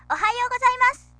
綾はしゃべったりもします。